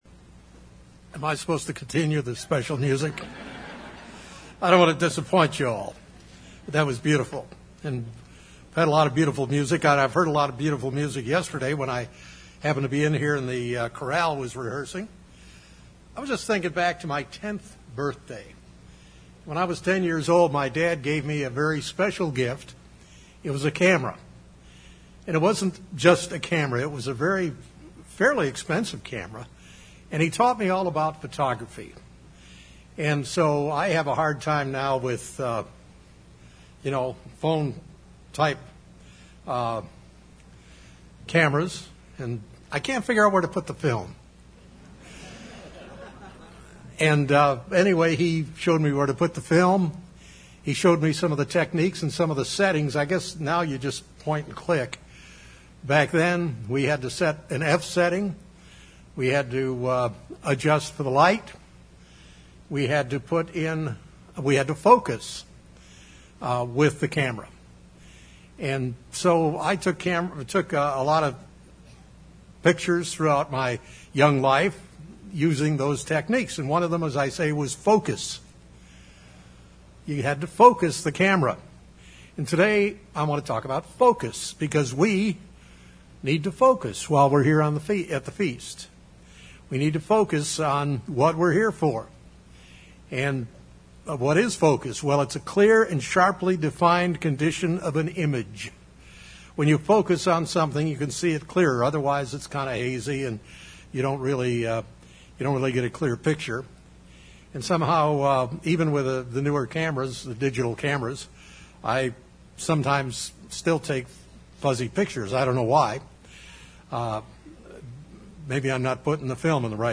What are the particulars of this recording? This sermon was given at the Branson, Missouri 2016 Feast site.